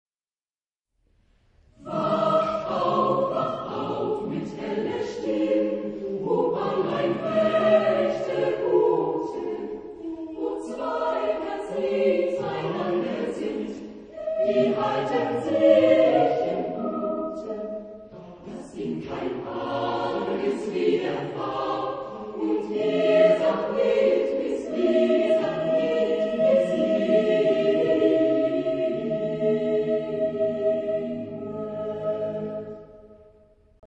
Genre-Style-Forme : Folklore ; Madrigal ; Profane
Type de choeur : SATTBB  (6 voix mixtes )
Tonalité : la bémol majeur
interprété par Rundfunk Jugendchor Wernigerode
Réf. discographique : 7. Deutscher Chorwettbewerb 2006 Kiel